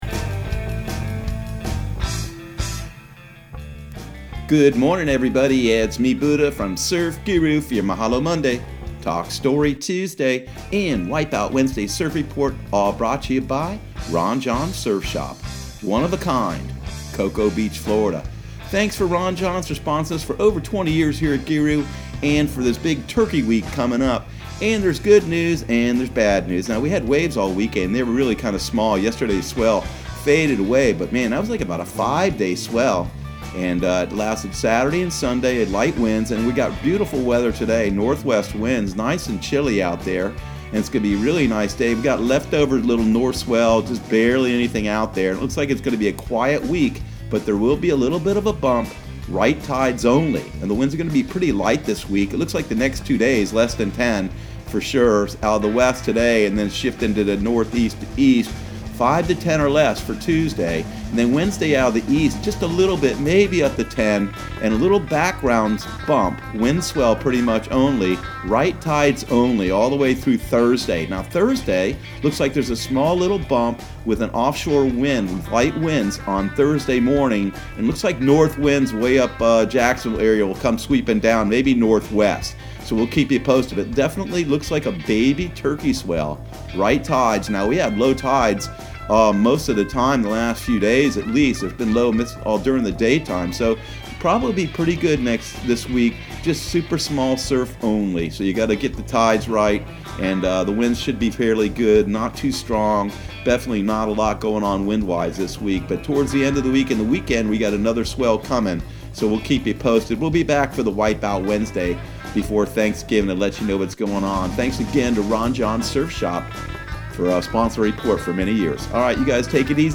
Surf Guru Surf Report and Forecast 11/25/2019 Audio surf report and surf forecast on November 25 for Central Florida and the Southeast.